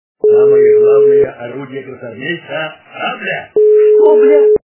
При прослушивании Василий Иванович - Самое главное оружие красноармейца - сабля качество понижено и присутствуют гудки.